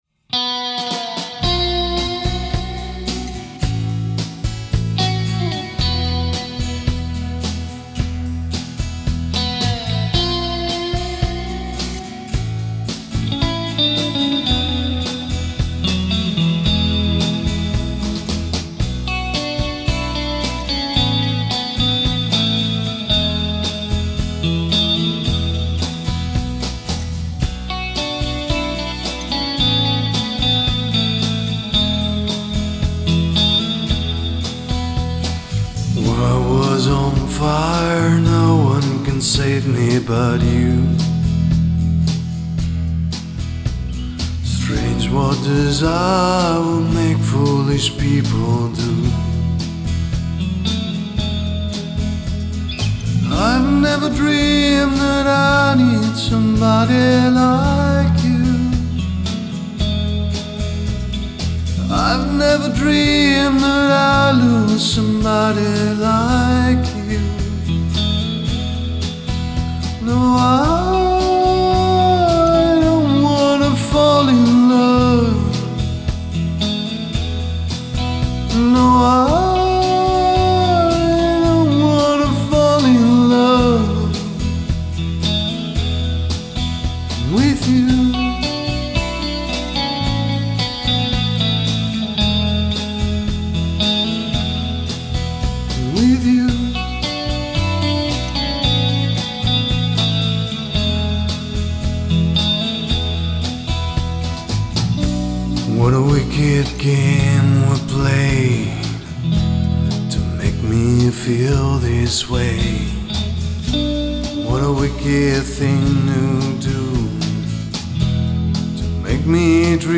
Fender Stratocaster GUITAR through Kemper Amp
VOICE and DRUMS
BASS